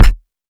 Kicks
KICK.15.NEPT.wav